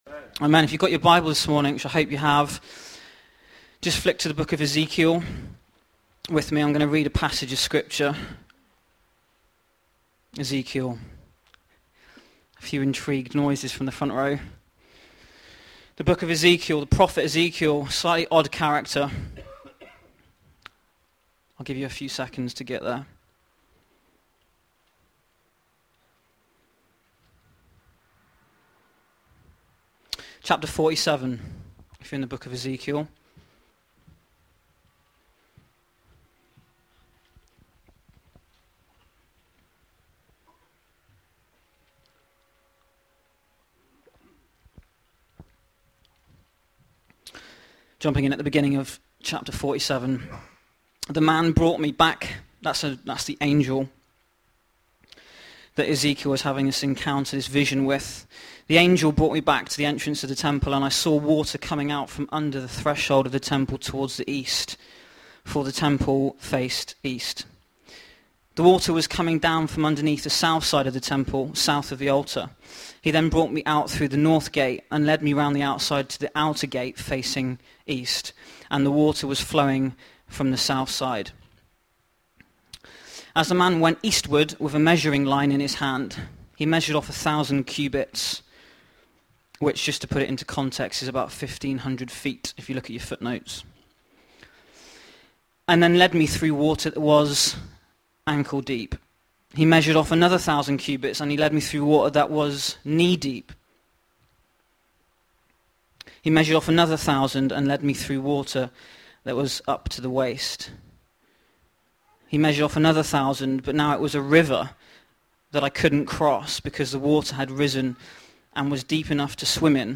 Into the Barn to Burn (sermon)